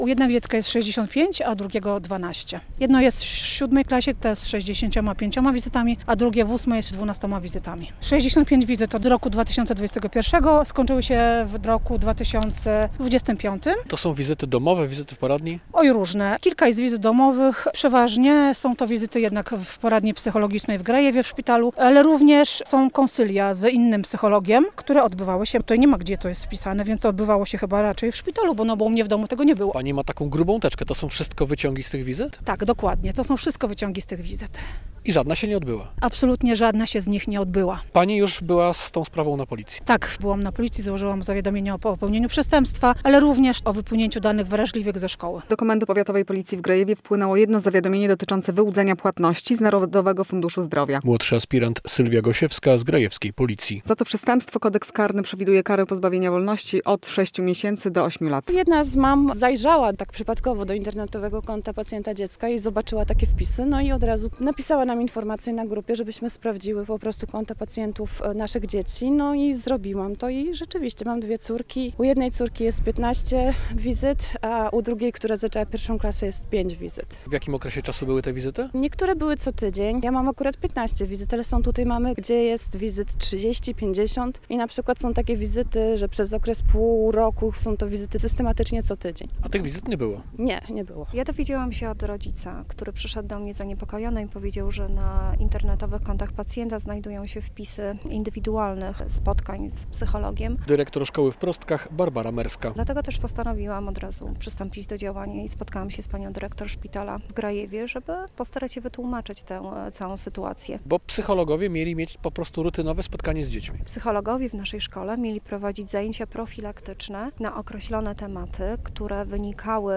Dobre źródło dochodu - mówiły mamy, z którymi rozmawiał dziennikarz Polskiego Radia Białystok.